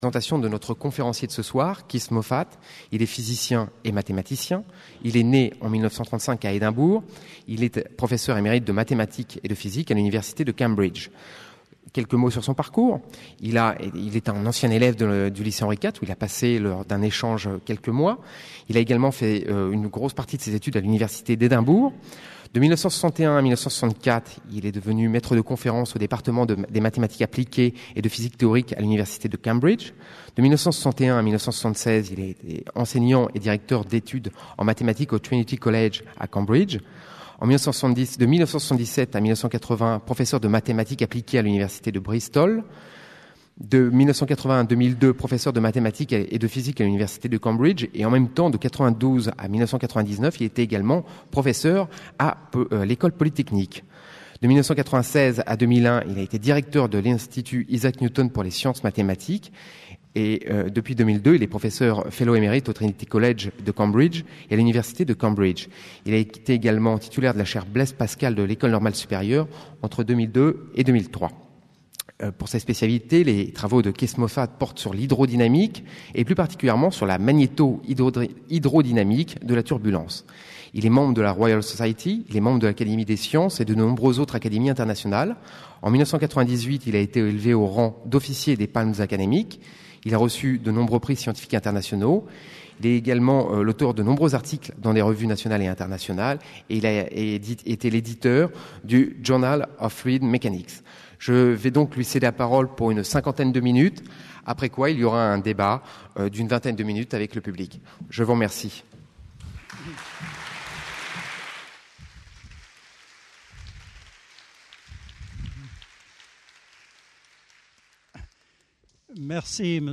Partenaire de l'université de tous les savoirs 2001-2002 Accueil dans ses locaux les conférences